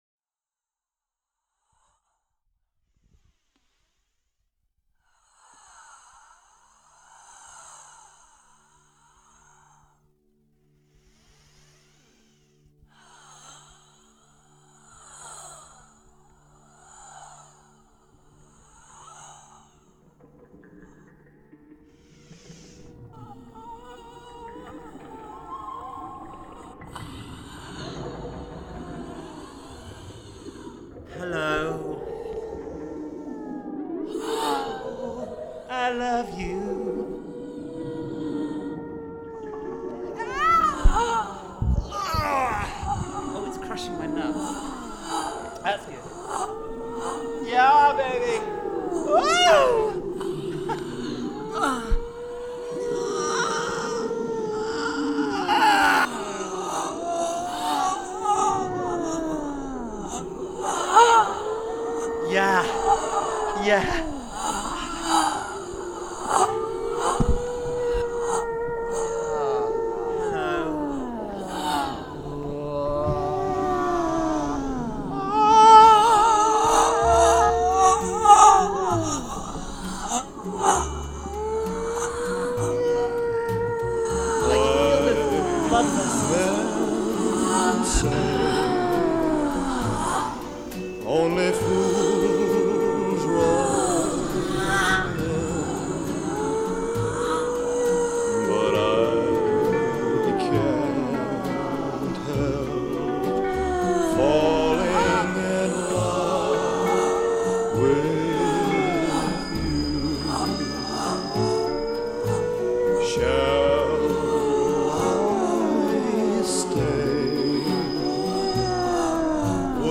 Metallic breath in a centrifuge-like motion. A meditative tone fades in beneath. The sound is pressed, as if underwater.
Underwater textures surface. The metallic breath grows more intense, strained - a distorted lament. A voice enters, saying “hello.”
Vocal fragments multiply — “hello”, “I love you.” Distorted moans and cries weave into the mix. Whales sustain long calls..
A distorted kulning (Nordic herding call) appears, stretched and metallic. Breath textures return, blending with whale tones.
en 8. Sound/music